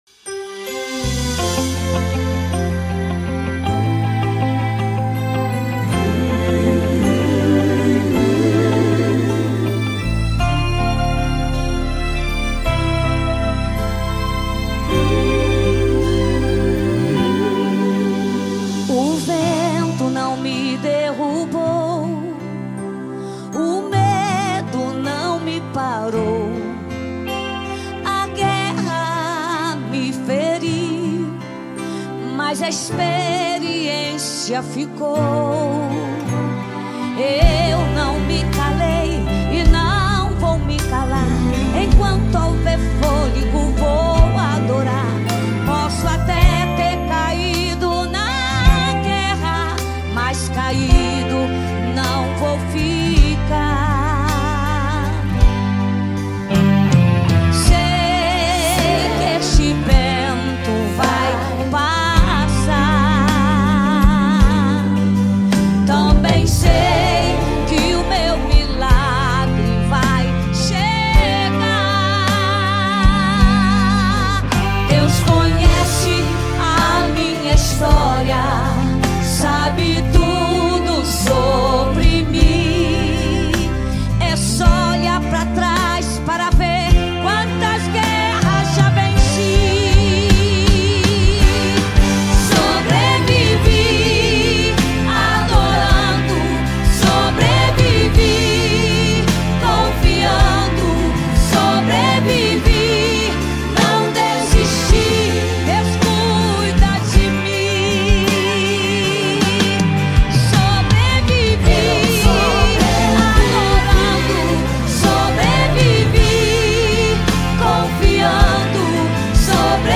Momento de Louvor e Adoração